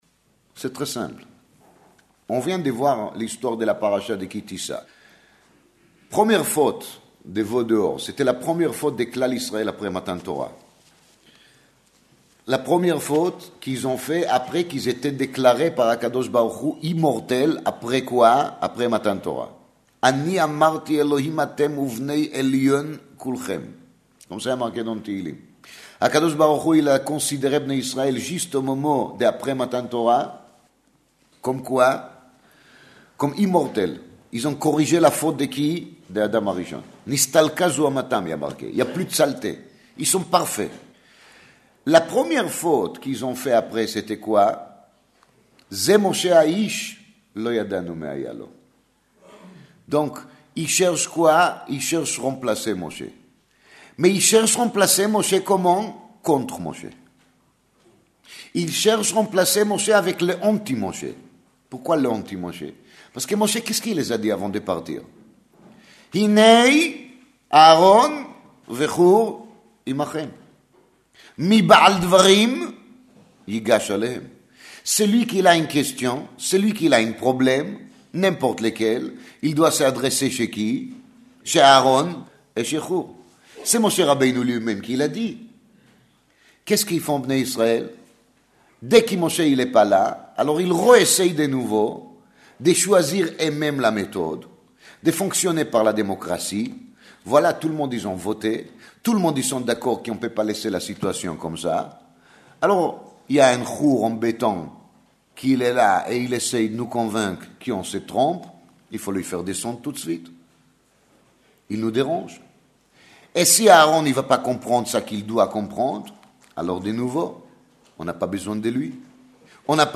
01:24:44 Cet exposé magistral a été donné le Motsé Shabbath, à la sortie du Shabbath Ki-Tissa, 23 Adar 1, 5757 – 1er mars 1997 à la Adass Yereïm, rue Cadet à Paris, organisé conjointement avec le Igoud ‘Harédi.